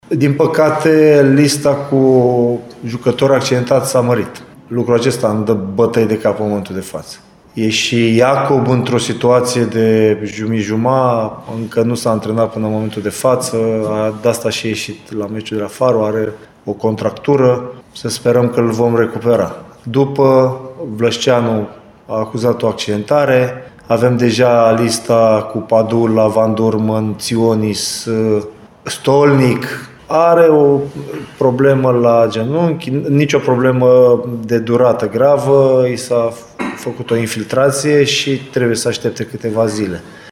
Antrenorul „Bătrânei Doamne”, Adrian Mihalcea, a vorbit despre problemele medicale din lotul său, la conferința premergătoare partidei cu Dinamo: